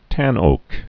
(tănōk)